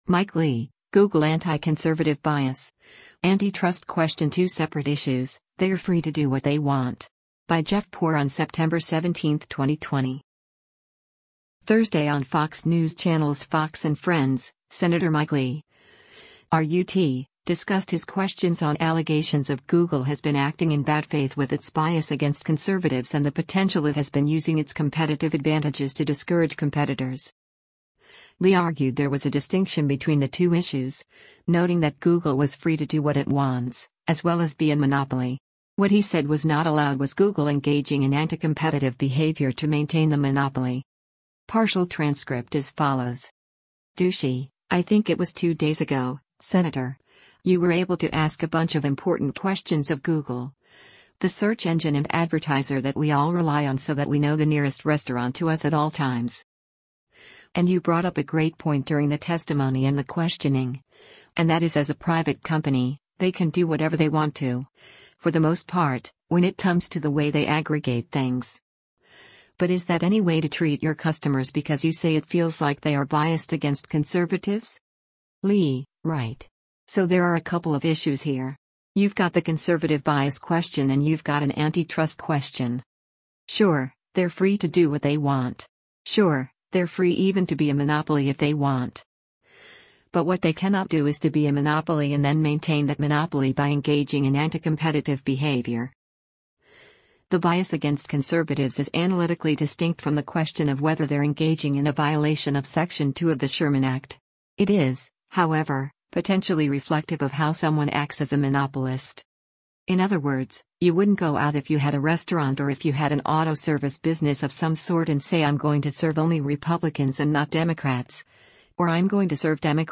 Thursday on Fox News Channel’s “Fox & Friends,” Sen. Mike Lee (R-UT) discussed his questions on allegations of Google has been acting in bad faith with its bias against conservatives and the potential it has been using its competitive advantages to discourage competitors.